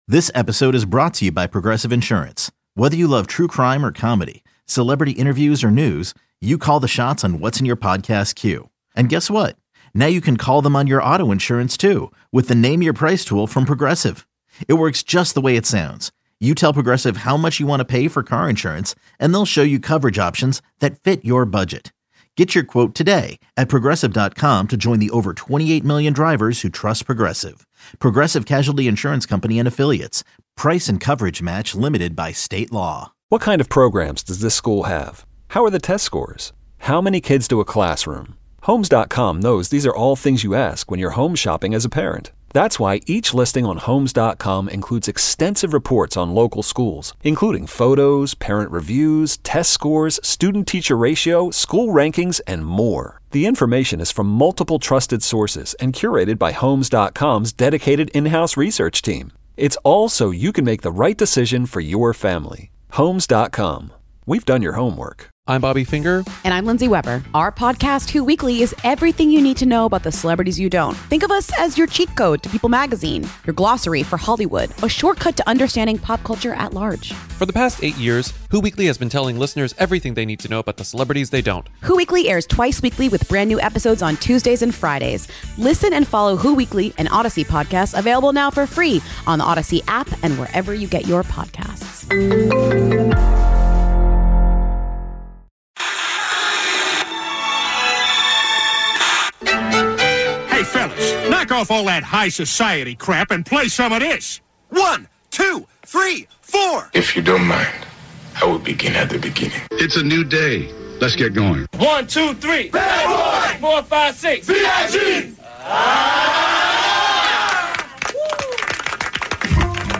Does Going to the Movies By Yourself Make you a Loser? Mike Shinoda In Studio.